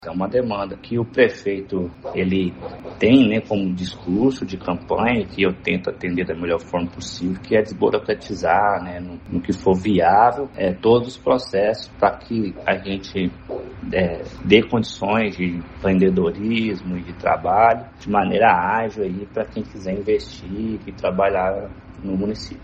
O secretário acrescentou que esta também é uma pauta defendida desde a campanha pelo prefeito Inácio Franco, visando desburocratizar e acelerar os serviços públicos na prefeitura de Pará de Minas: